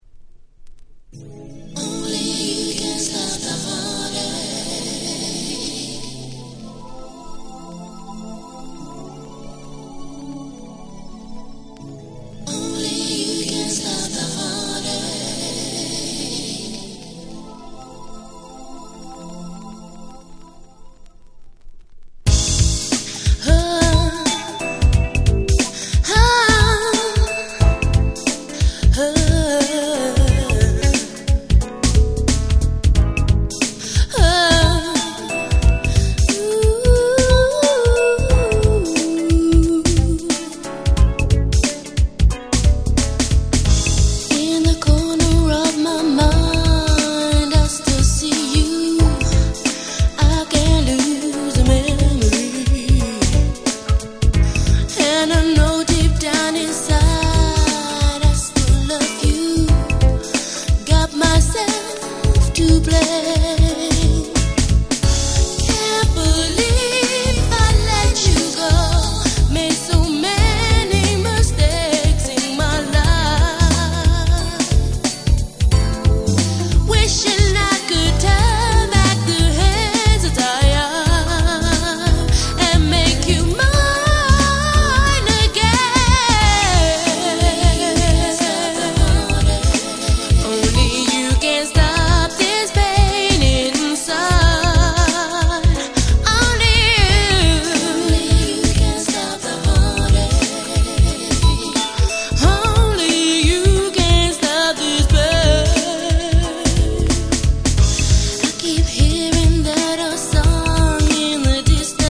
92' Super Nice UK Street Soul / R&B !!
超マイナー！！超絶美メロ！！
緩いGround Beatに美しいメロディー、綺麗で囁く様な女性Vocal。。。もう最高です！！
90's グラビ Grand グランド グラウンドビート